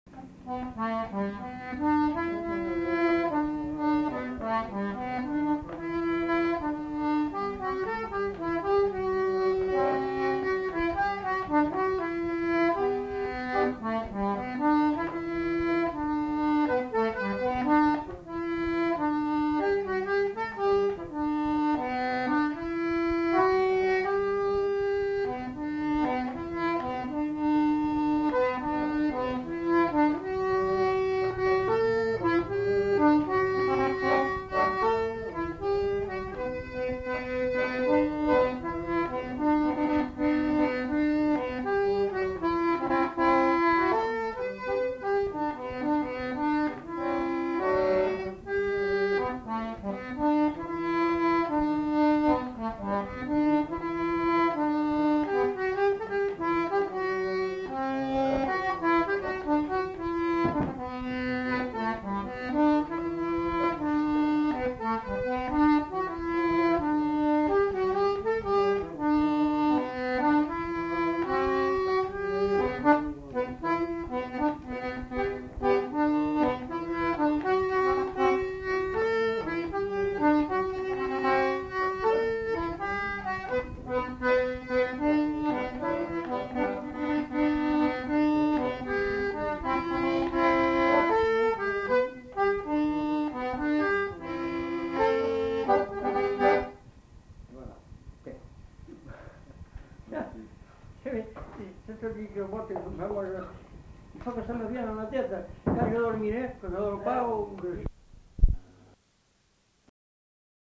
Valse
Aire culturelle : Marmandais gascon
Lieu : Mas-d'Agenais (Le)
Genre : morceau instrumental
Instrument de musique : accordéon diatonique
Danse : valse